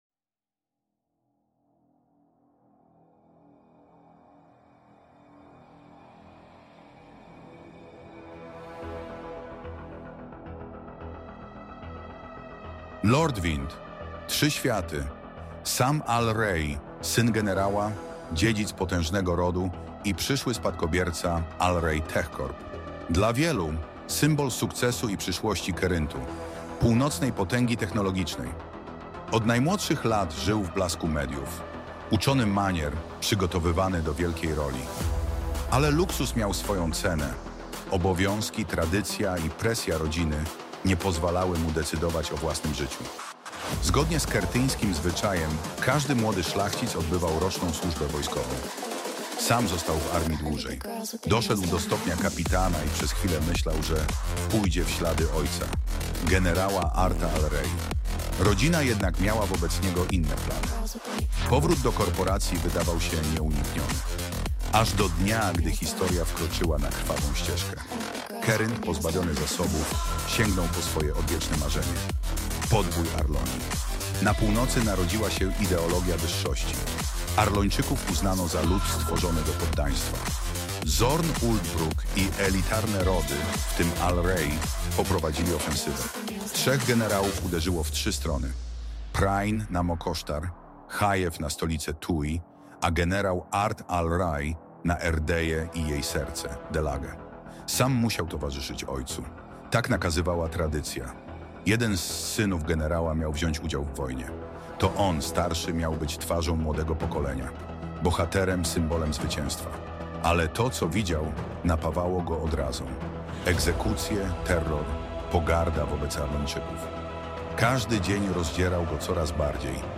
Zaproszenie narratora
glowny-narrator-.mp3